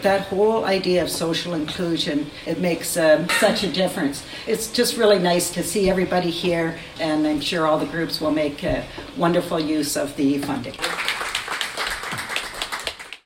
On Thursday morning at the Consecon Public Library, Smith announced funding for four different projects.
Ameliasburgh Ward Councillor Janice Maynard spoke at the gathering Thursday morning and says a sense of community is vital for seniors.